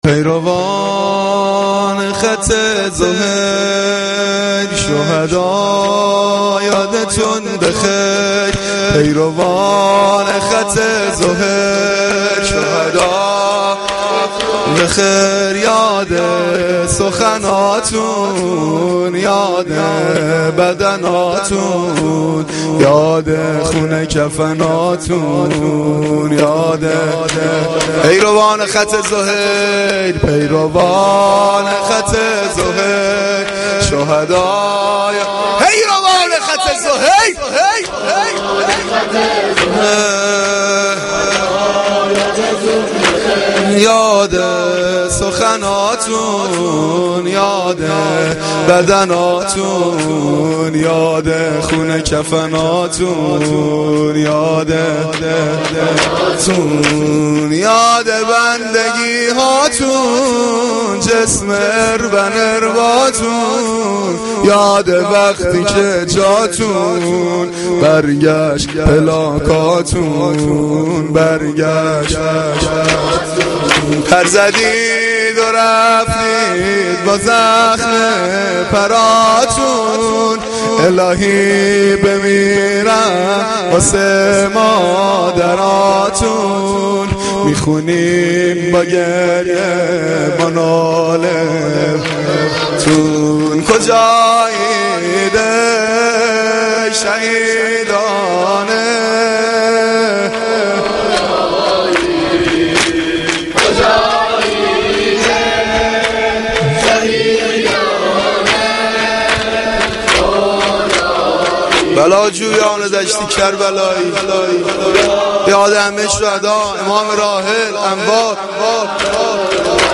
مداحی
Shab-8-Moharam-8.mp3